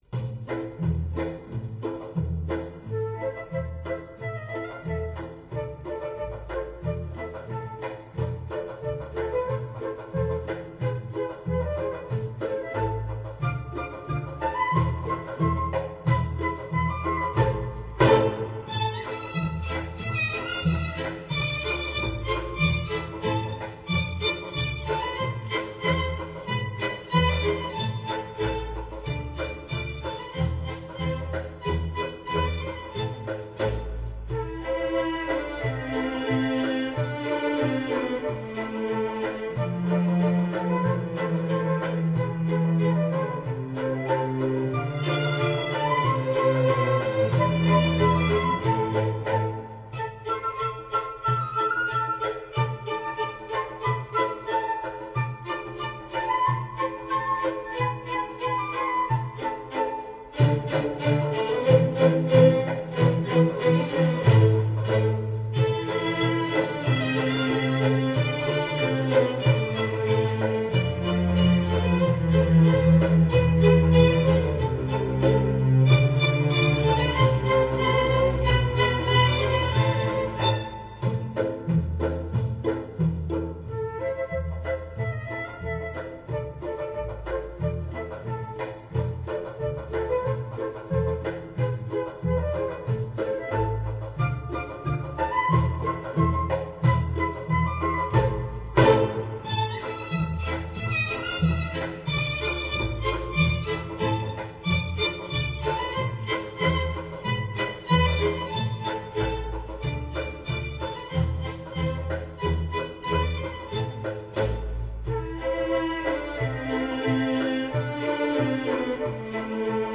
大型管弦乐队+国内器乐演奏名星+联诀奉献
中华五十六个民族经典民谣管弦乐
西洋管弦强大表现力
笛子／洞箫
巴乌
笙
中阮／柳琴
二胡
板胡／中胡